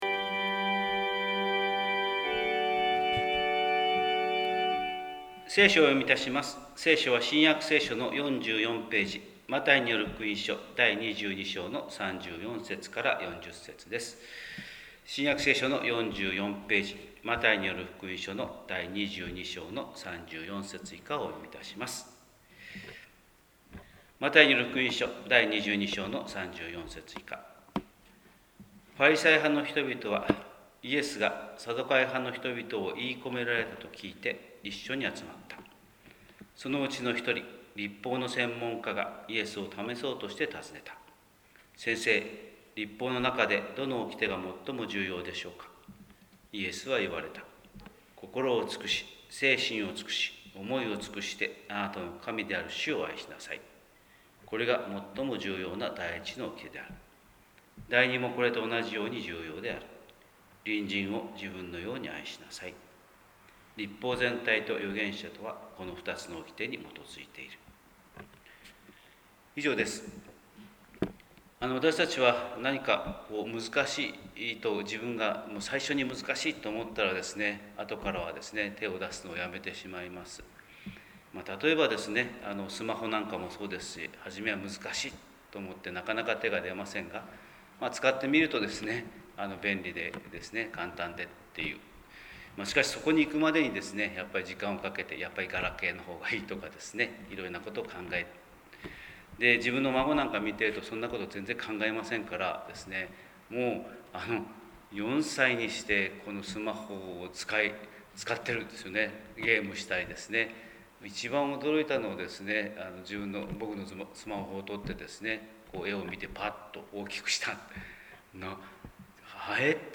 神様の色鉛筆（音声説教）
広島教会朝礼拝211015